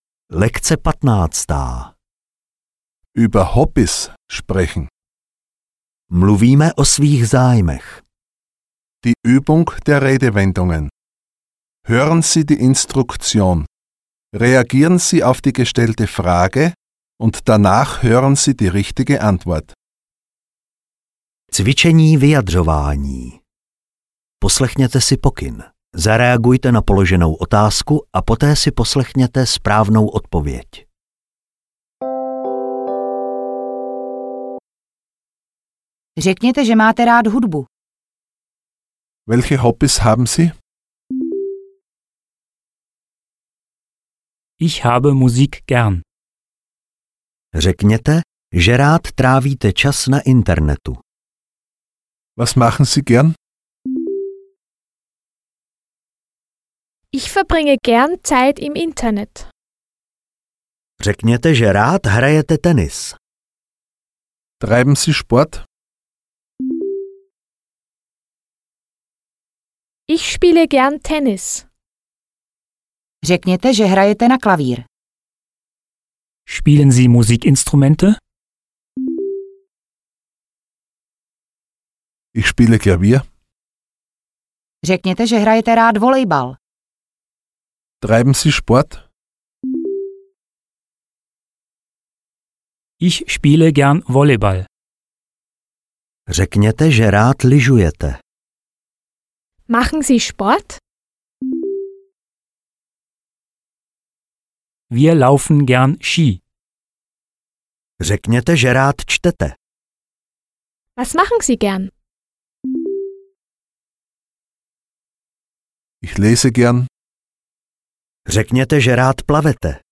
Ukázka z knihy
Cvičení „Rozuměj“ se zaměřuje na porozumění základních údajů zachycených informací podávaných rodilými mluvčími.